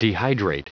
Prononciation du mot dehydrate en anglais (fichier audio)
Prononciation du mot : dehydrate